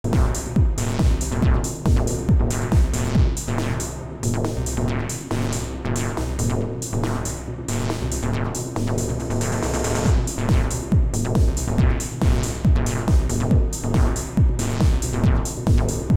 To be honest, sometimes it’s worse, but here’s a bunch of snippets I just recorded, taken from my last improv practice session.
And by this time it’s just so much always haha